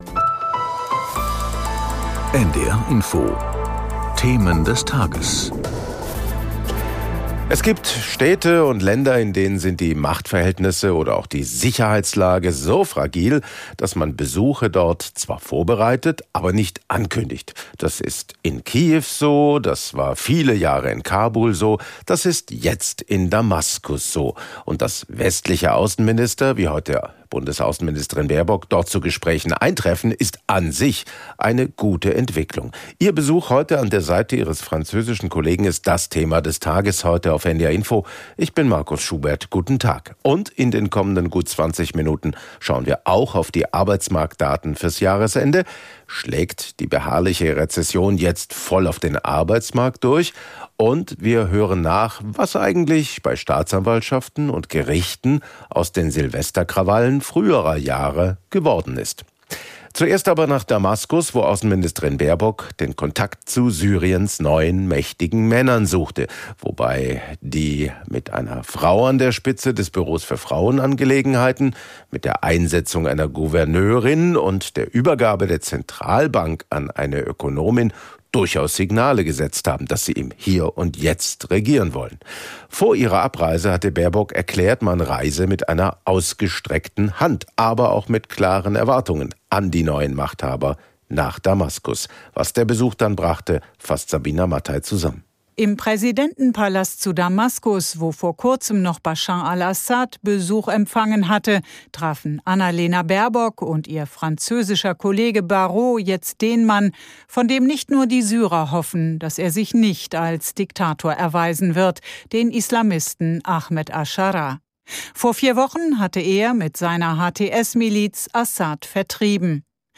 Hier hören Sie in gut 25 Minuten die wichtigsten Nachrichten für den Norden. Aus Politik, Wirtschaft, Sport und Kultur.
In Gesprächen mit Korrespondenten und Interviews mit Experten oder Politikern.